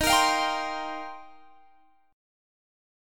Eb6add9 Chord
Listen to Eb6add9 strummed